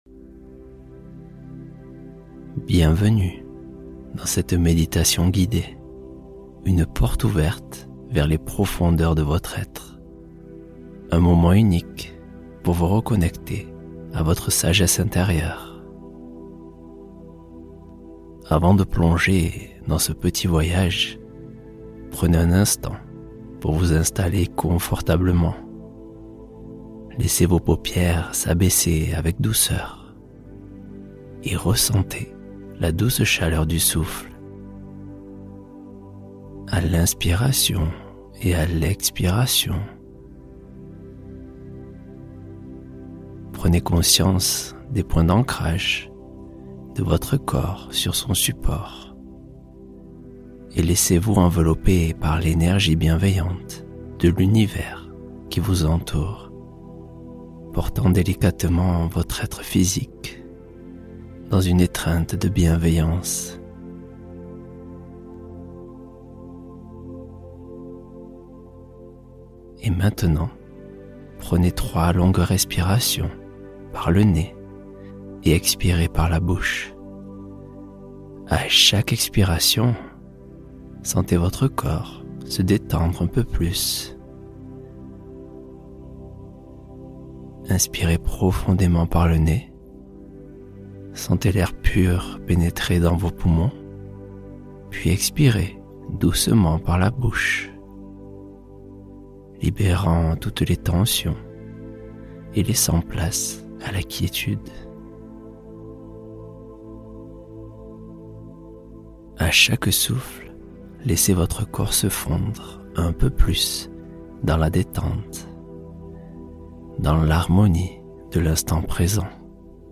Guide Intérieur : Méditation pour se connecter à sa propre sagesse